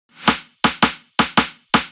SNARE ROLL.wav